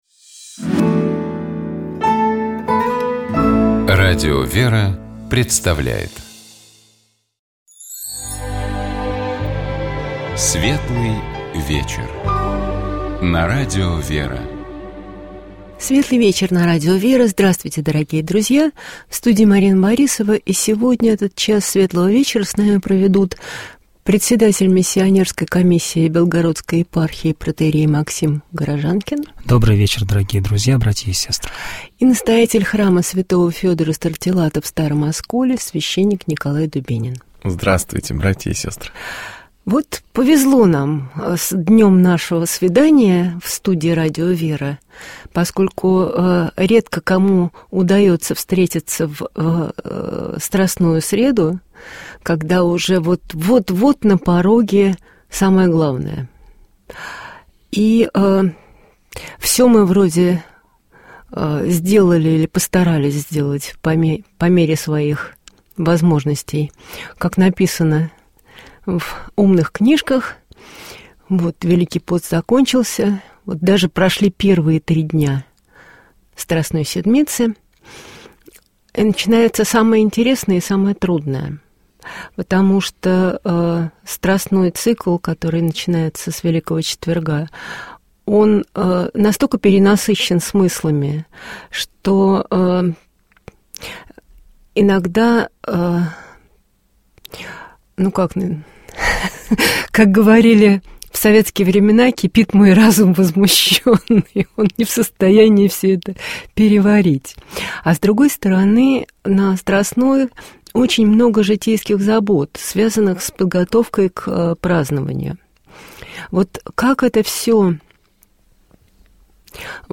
Богослужебные чтения - Радио ВЕРА